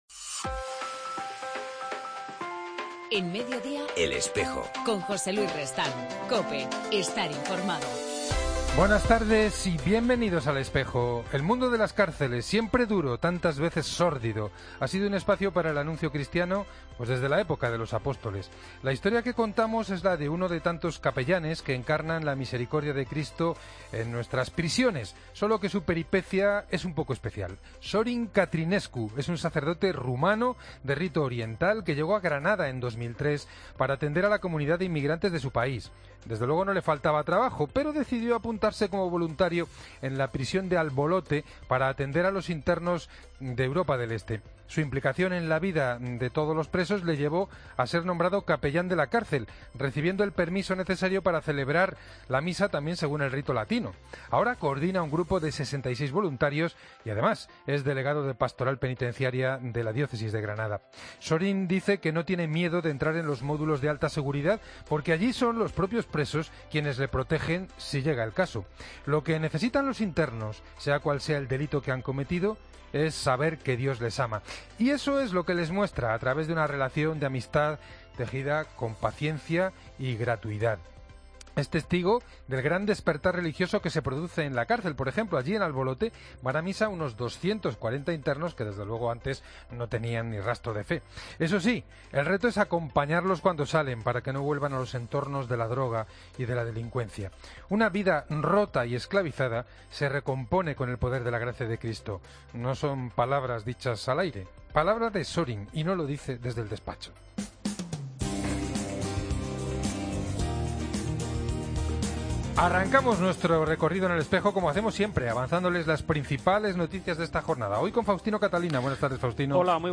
entrevista al P. Federico Lombardi.